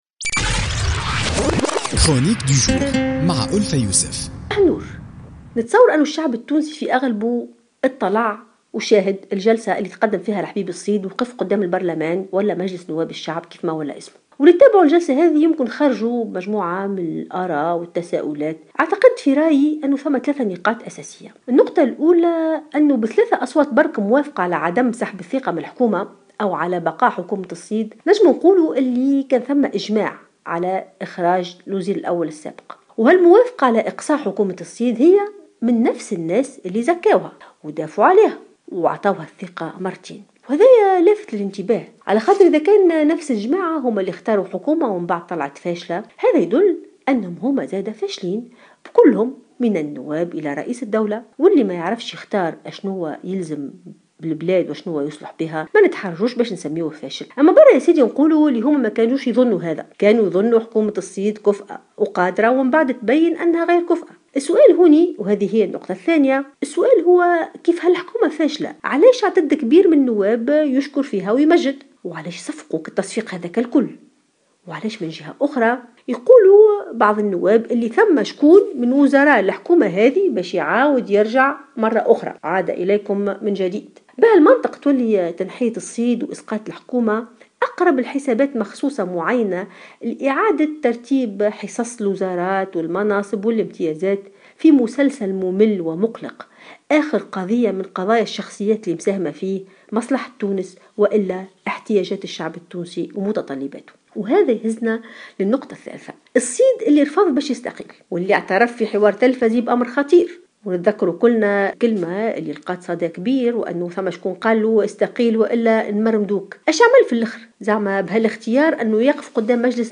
تطرقت الكاتبة ألفة يوسف في افتتاحية اليوم الثلاثاء 2 اوت 2016 إلى جلسة سحب الثقة من الحبيب الصيد رئيس الحكومة مشيرة إلى أن الموافقة على اقصاء حكومة الصيد جاءت من نفس الأشخاص الذين كانو قد دافعوا عليها وقاموا بتزكيتها واعطائها الثقة في مناسبتين .